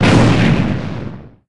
gen_small_explo_01.ogg